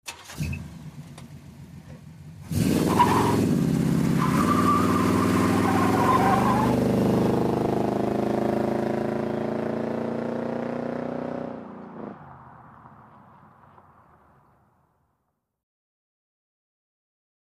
1958 Chevrolet Impala, Start, Very Fast Away with Tire Squeal.